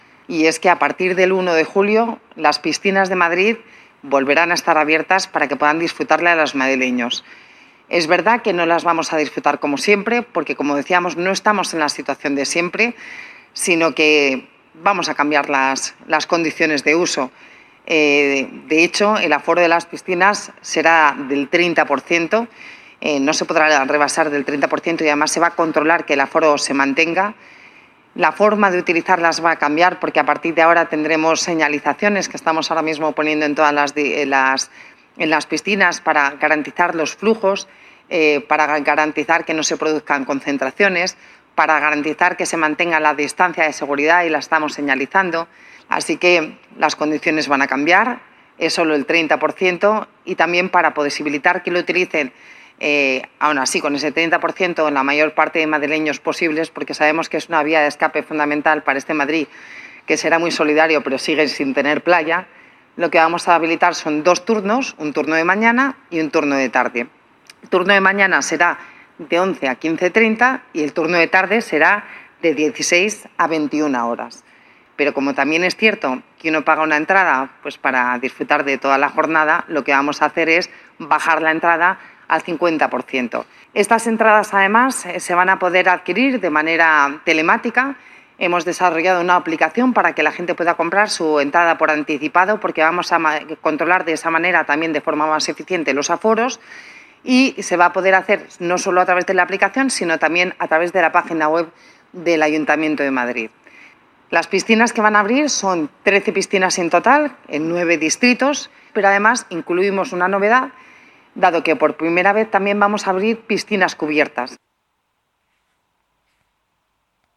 Nueva ventana:Declaraciones de la vicealcaldesa, Begoña Villacís